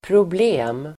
Uttal: [probl'e:m]